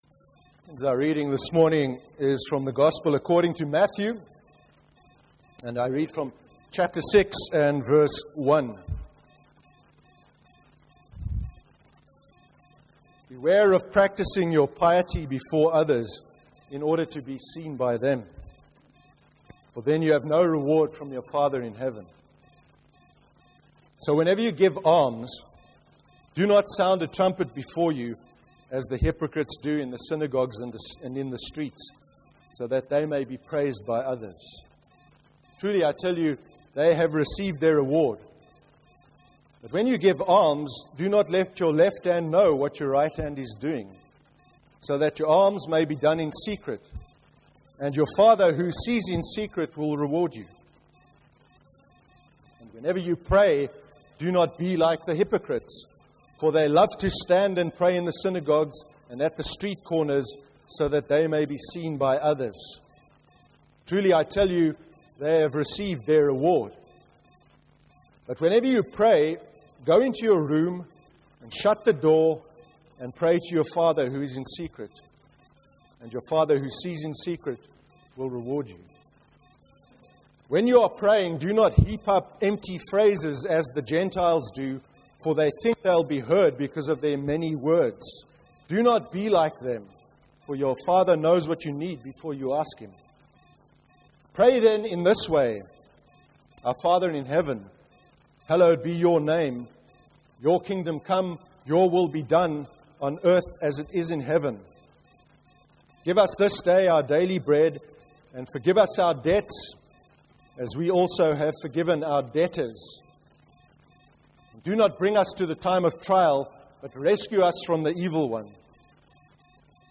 09/06/13 sermon – Concerning Almsgiving and prayer. Living authentic lives, and trusting in God (Matthew 6:1-15).